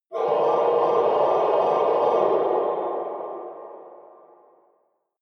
Gemafreie Sounds: Metall